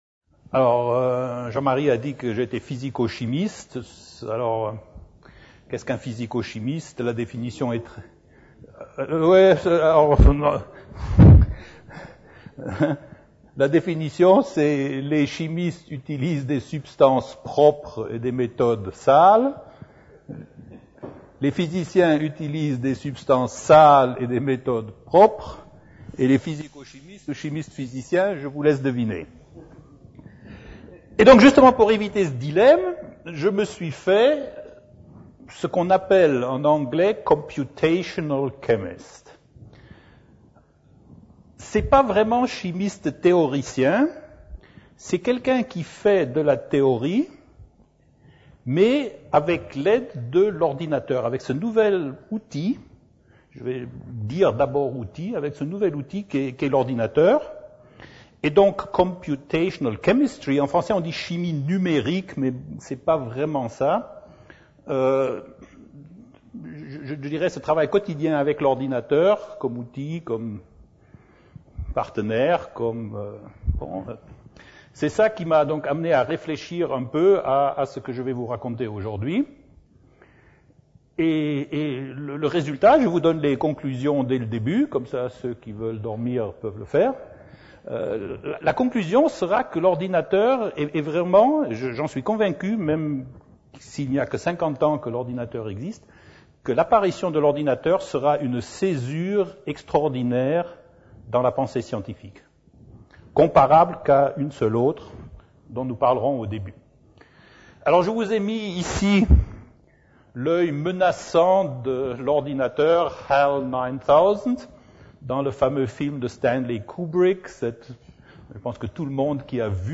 Mais cette machine programmable ne nous ôte-t-elle pas la capacité de comprendre la science ? La conférence a été donnée à l'Université Victor Segalen Bordeaux 2 dans le cadre du cycle de conférences "L'invité du Mercredi" / Saison 2008-2009 sur le thèm